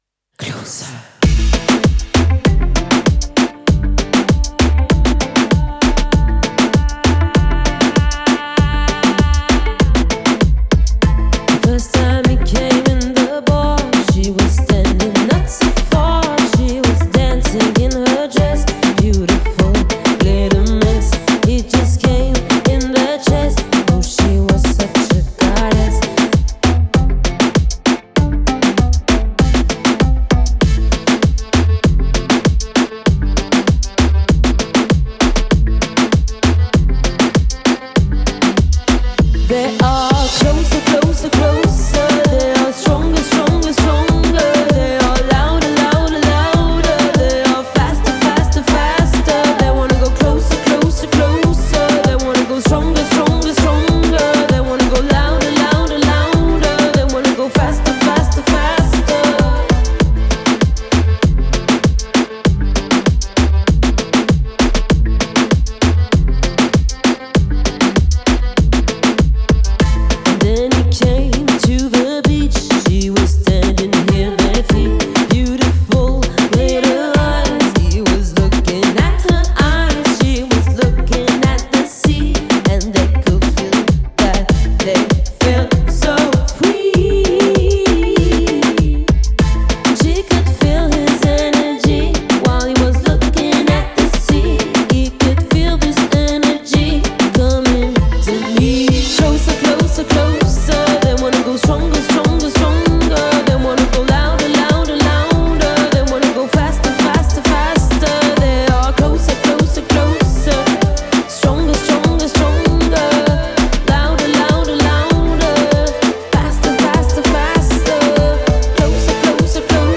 soul et groovy